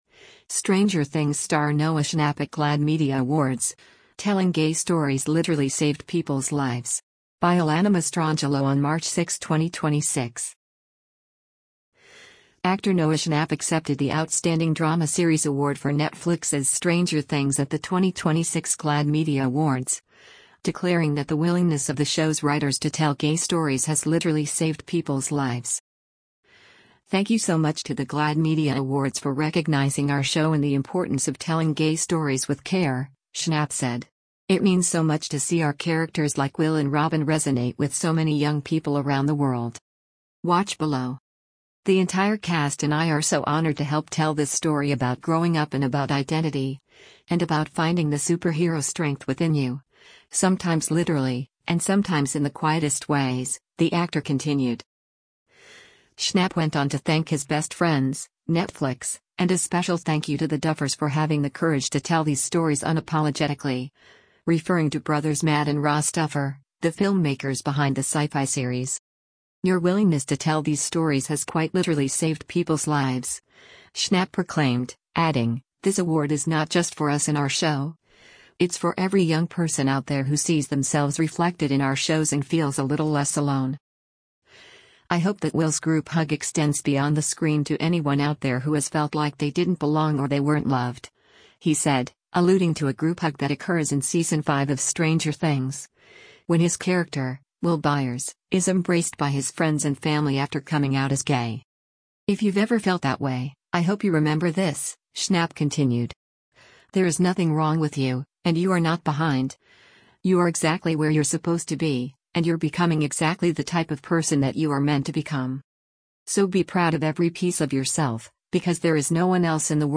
Actor Noah Schnapp accepted the “Outstanding Drama Series” award for Netflix’s Stranger Things at the 2026 GLAAD Media Awards, declaring that the “willingness” of the show’s writers to tell gay stories has “literally saved people’s lives.”
“So be proud of every piece of yourself, because there is no one else in the world that can be you like you can,” the actor exclaimed to raucous applause.